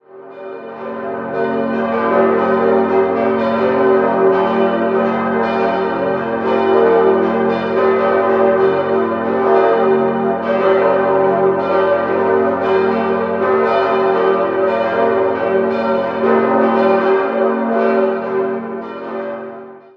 In den 44 m hohen schlanken Türmen hängt ein gewaltiges Geläute mit sechs Glocken.
6-stimmiges erweitertes G-Moll-Geläute: g°-b°-d'-f'-g'-b'
Die Glocken wurden im Jahr 1991 von der Gießerei Bachert in Heilbronn gegossen.
Das Glockensextett gilt wohl zurecht als schönstes Geläute am Starnberger See und darf gewiss auch zu den schönsten Großgeläuten Bayerns gezählt werden. Der warme, volltönige Klang bietet ein beeindruckendes Klangerlebnis.